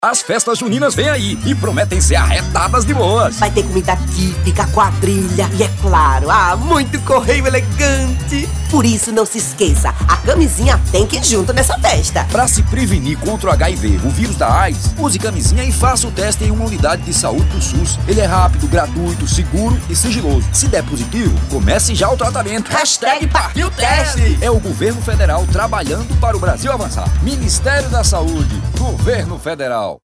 Spot Festa Junina (MP3, 1.15 MB)1.15 MB